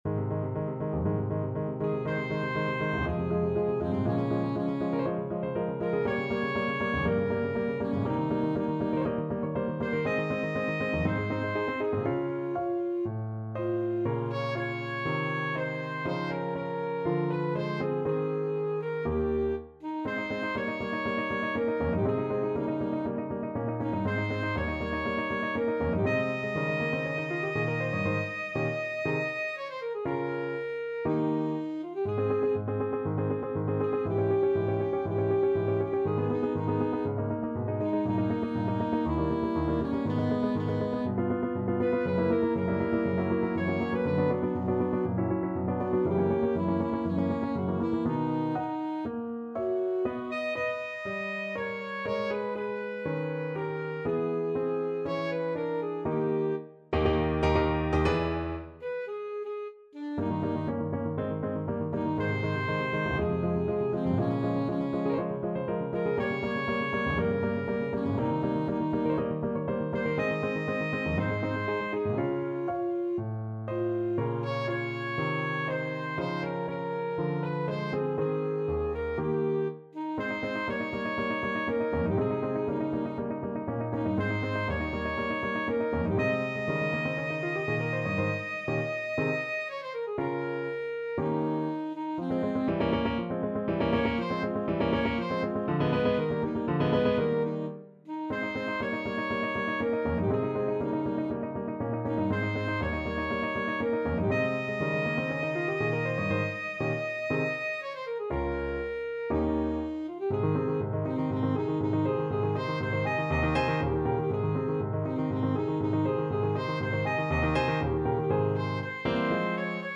Classical Mozart, Wolfgang Amadeus Or sai, chi l'onore from Don Giovanni Alto Saxophone version
Ab major (Sounding Pitch) F major (Alto Saxophone in Eb) (View more Ab major Music for Saxophone )
=120 Andante
4/4 (View more 4/4 Music)
Classical (View more Classical Saxophone Music)